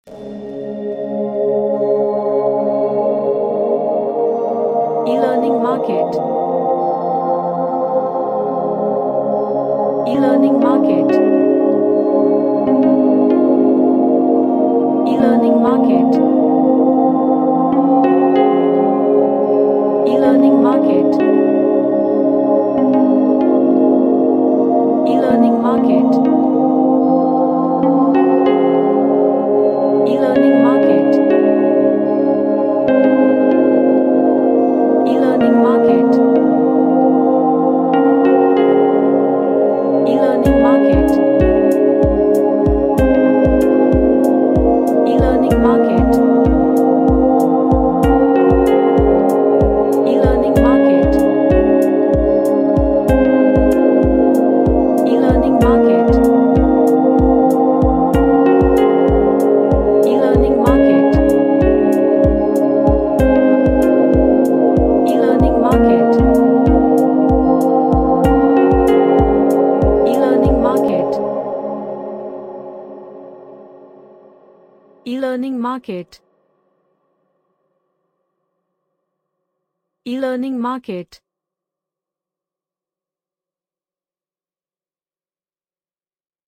An Ambient sound track featuring ambient guitar melodies.
Happy